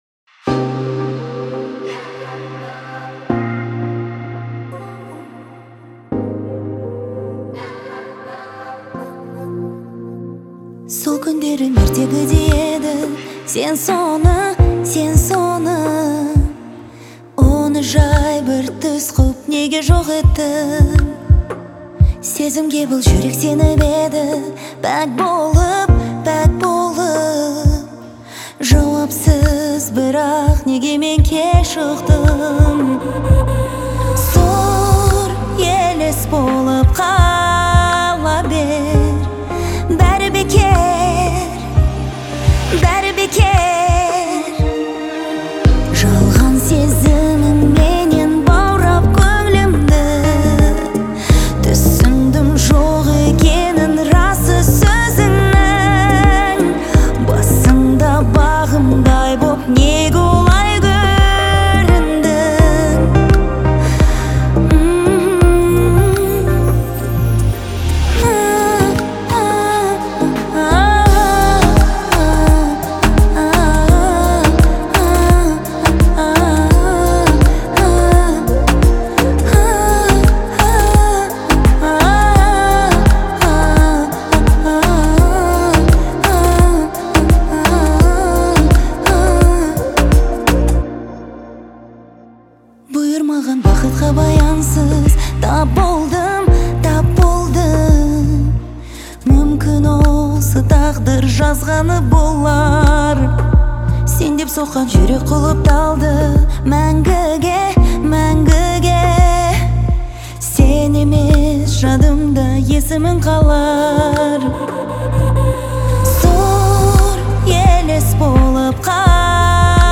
который сочетает в себе элементы поп и фолка.